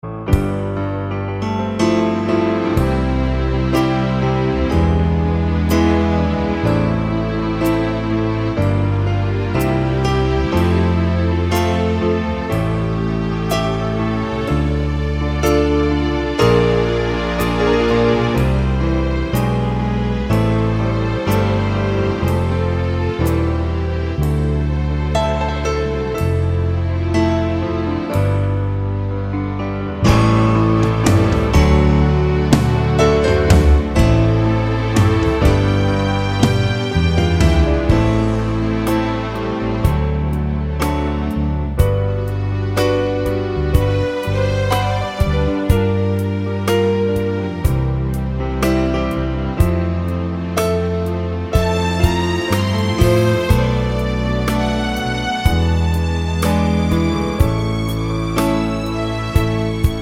Minus All Saxes Pop (1980s) 5:20 Buy £1.50